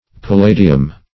Palladium \Pal*la"di*um\ (p[a^]l*l[=a]"d[i^]*[u^]m), n. [L., fr.